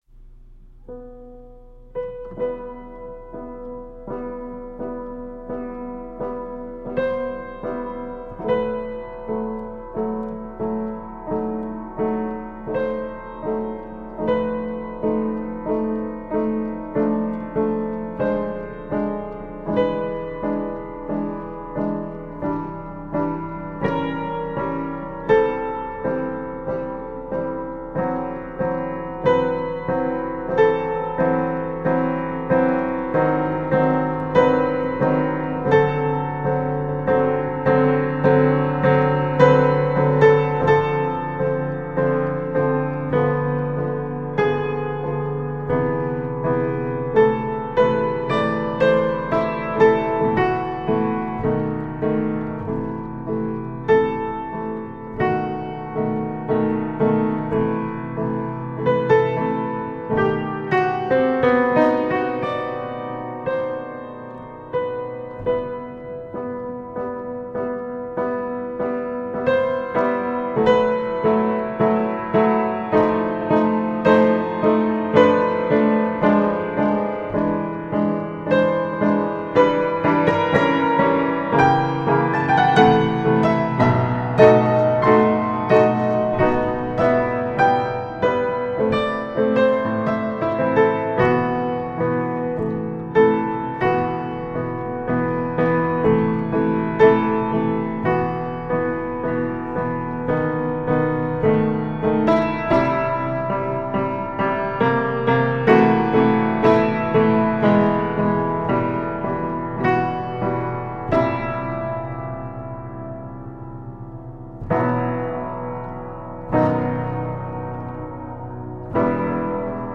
Classical piano.